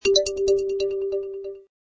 new-mail.mp3